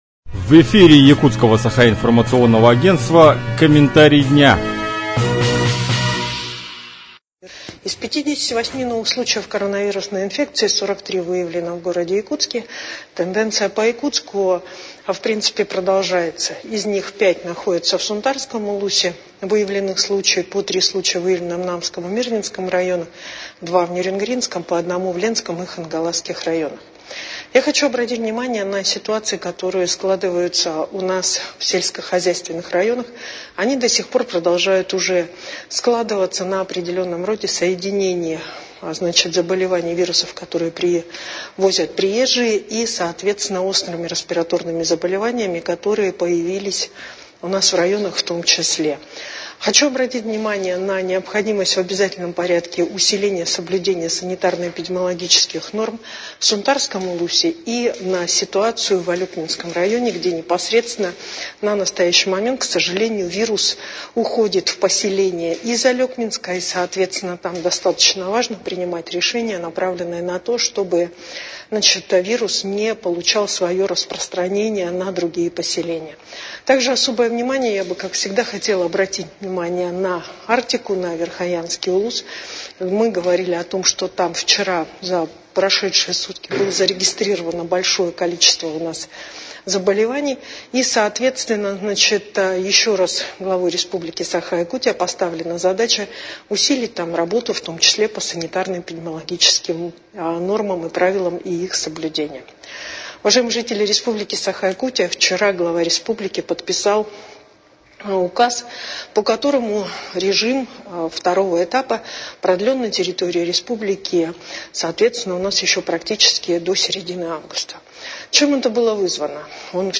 Какова обстановка в Якутии на 1 августа, рассказала вице-премьер Якутии Ольга Балабкина.